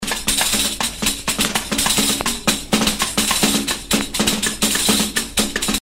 percusion-cueca-1.mp3